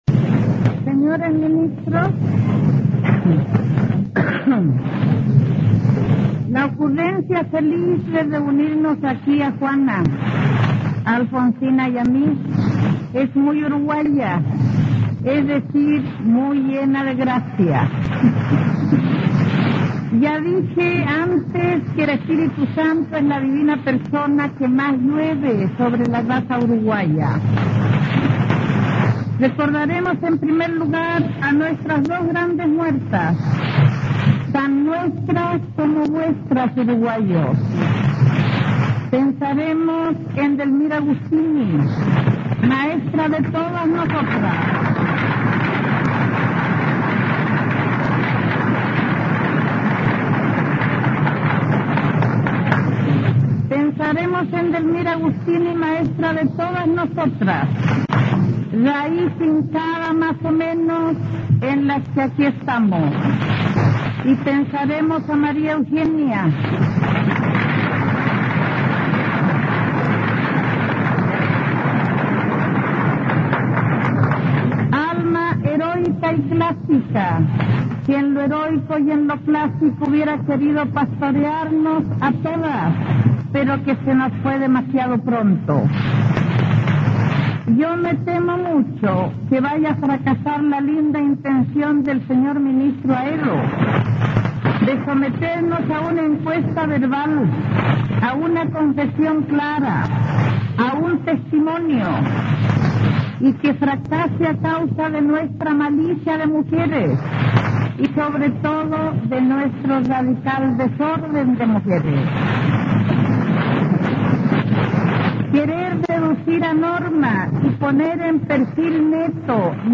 Gabriela Mistral. Disertación durante curso de verano en Montevideo
Te invitamos a escuchar una conferencia de la gran poetisa chilena Gabriela Mistral, realizada en Montevideo, Uruguay, en 1938, en la cual se le pidió que explicara cómo escribía sus versos. Además, nuestra Premio Nobel de Literatura recita y brinda una interpretación del poema "La pajita" que no podrá pasar por alto un buen lector de su obra.